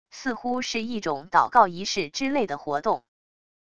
似乎是一种祷告仪式之类的活动wav音频